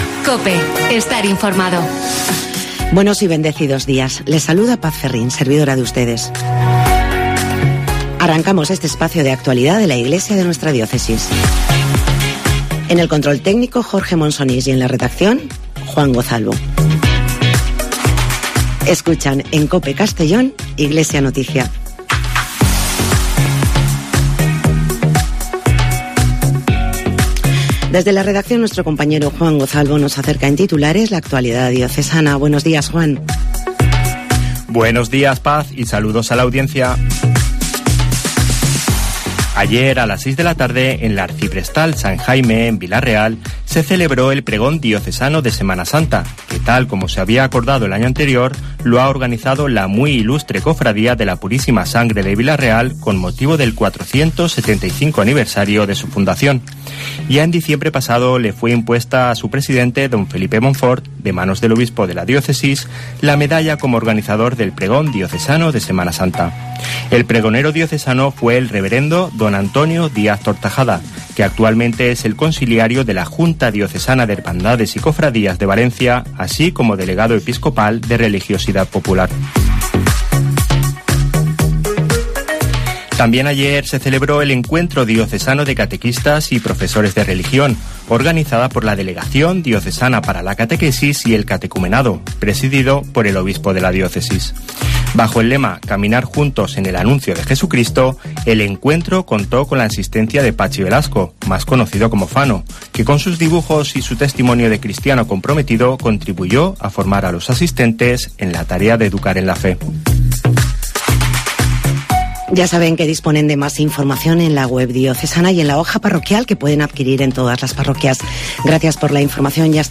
Espacio informativo